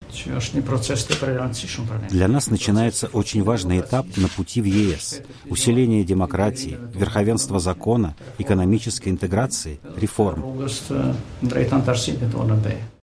Говорит премьер-министр Косова Иса Мустафа